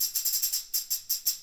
Tambourine 03.wav